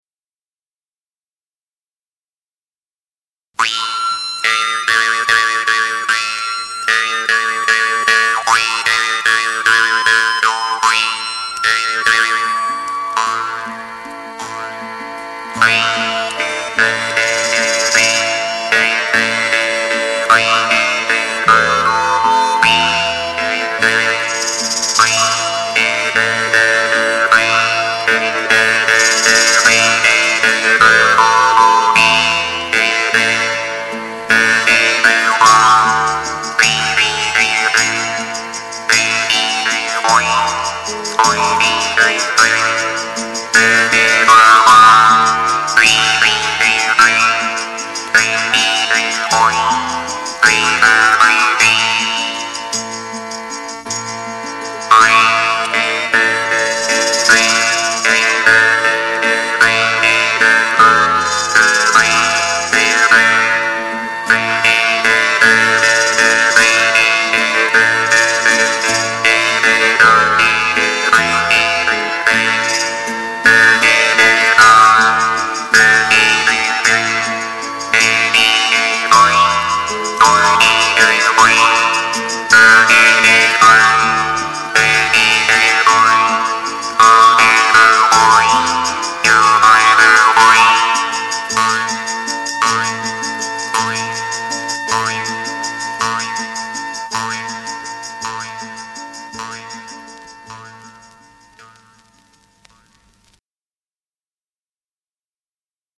(SCACCIAPENSIERI - JEW' S HARP)